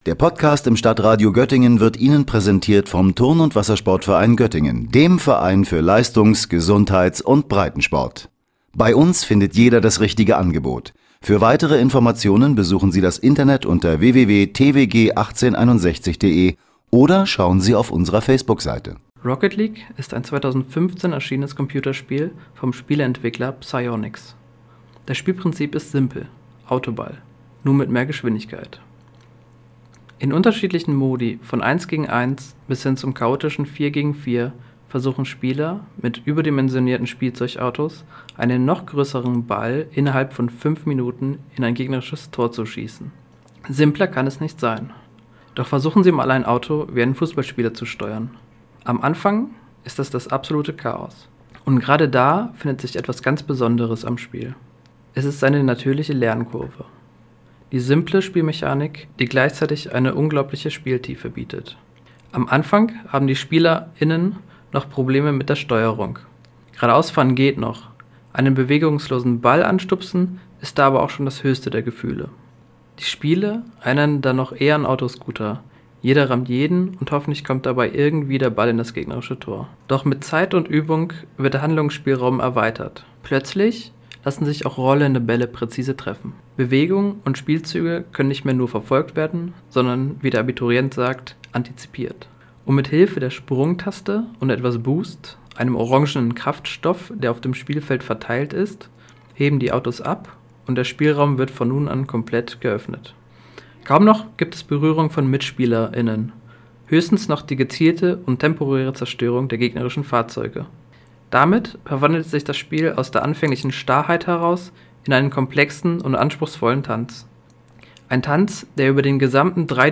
Das Stadion jubelt!
Was beim ersten Hinhören fast wie Fußball klingen könnte, ist das E-Sports Phänomen „Rocket League“.